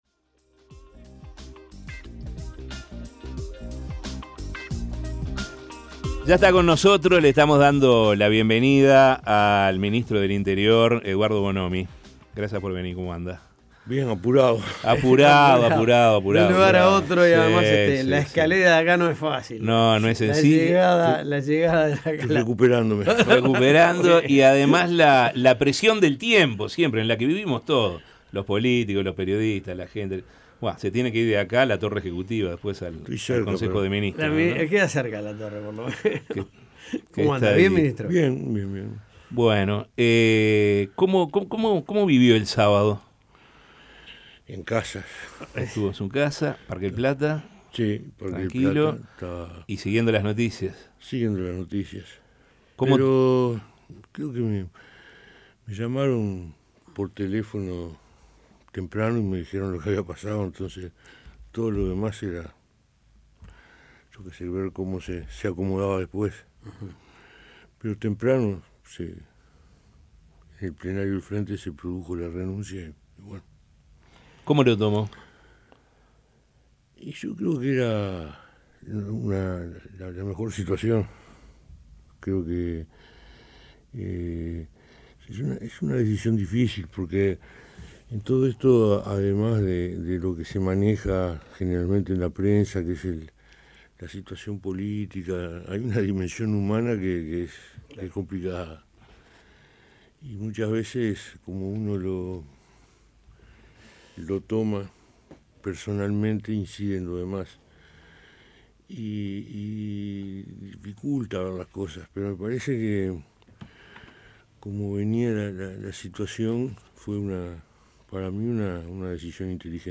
Escuche la entrevista de La Mañana: